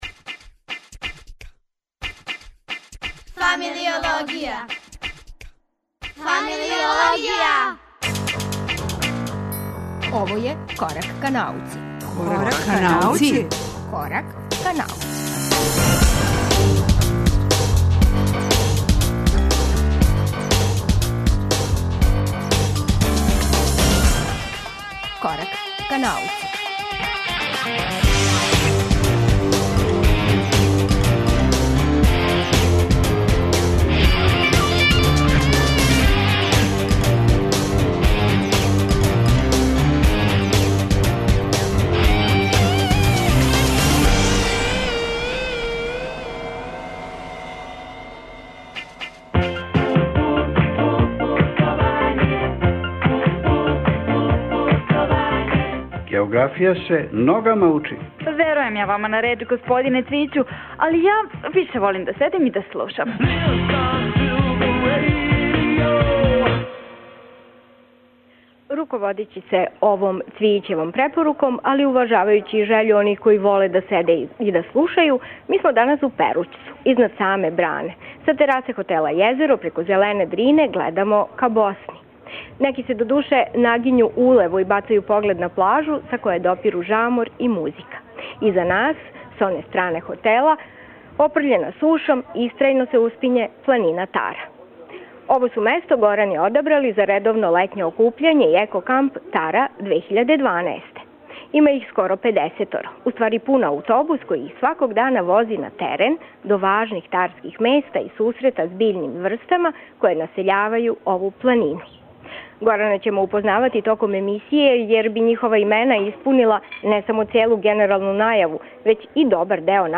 Емисија се реализује из хотела „Језеро" у Перућцу, где је смештена горанска база.